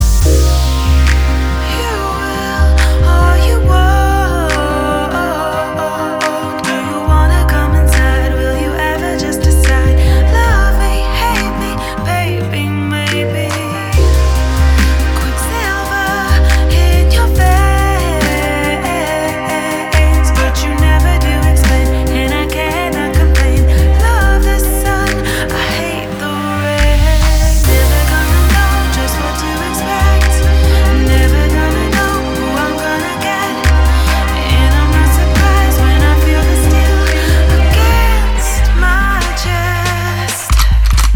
атмосферные
dance
Electronic
EDM
красивый женский голос
Отличная атмосферная песня